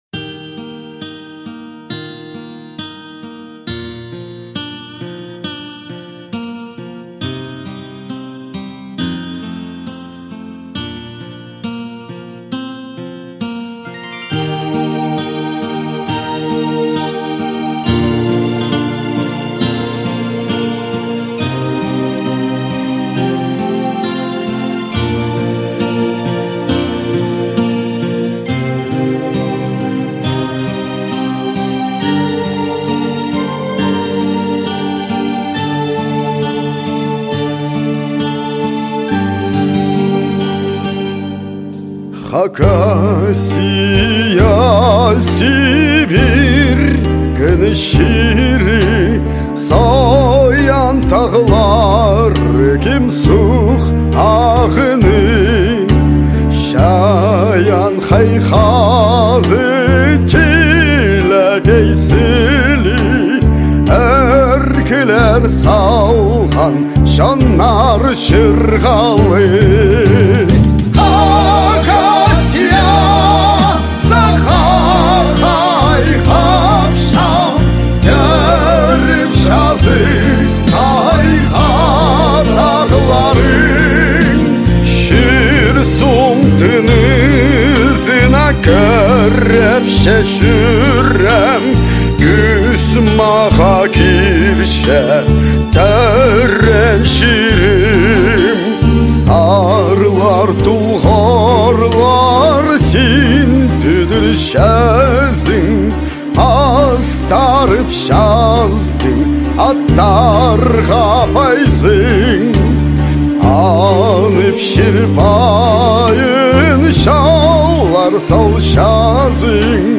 Гимн вокальная запись с синтезатором на хакасском языке